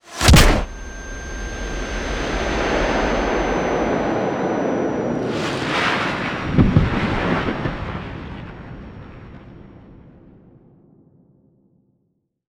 fangPunch.wav